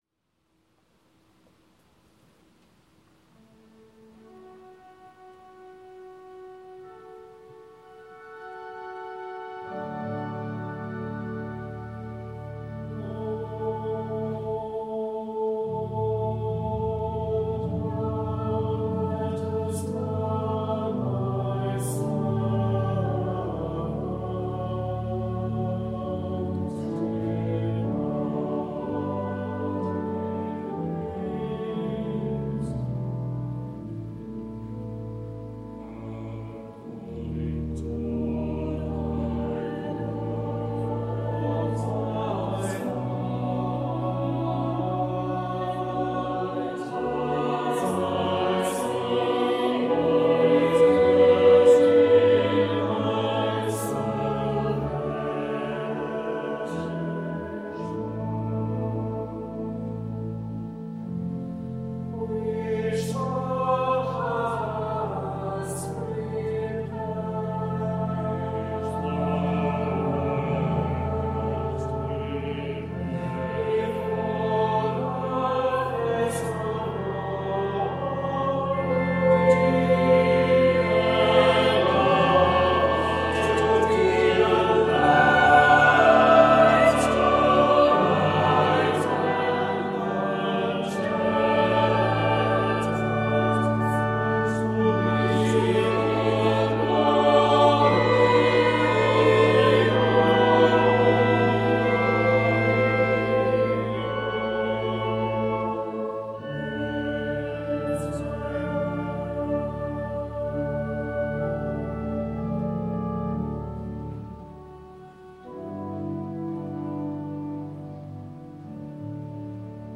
Dyson Nunc Dimittis in D, sung by the Priory Singers of Belfast at Truro Cathedral